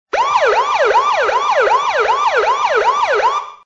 (ТШ) Вложения Полицейская сирена-.mp3 Полицейская сирена-.mp3 26,2 KB · Просмотры: 647